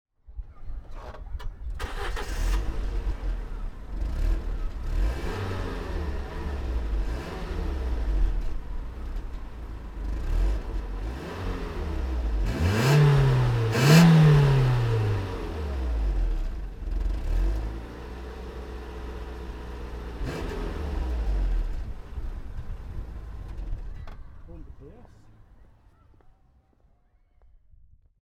Fiat Multipla (1999) - Starten und Leerlauf